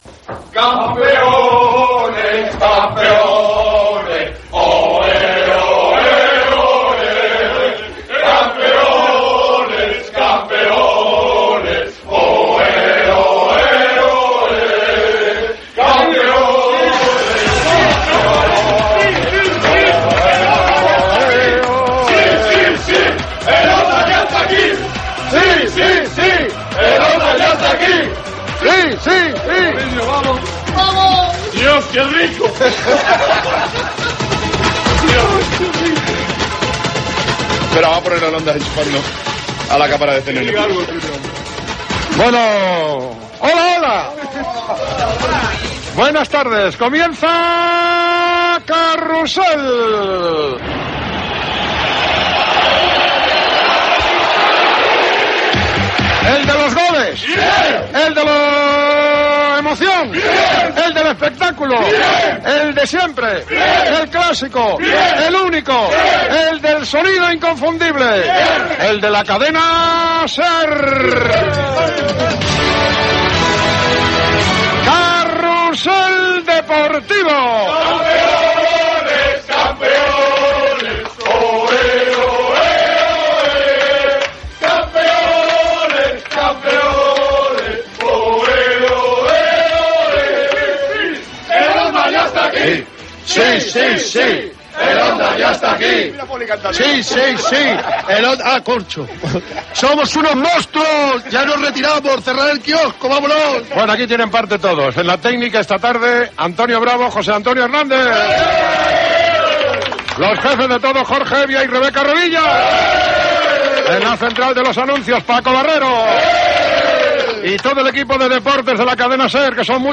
Presentació amb cants.
Esportiu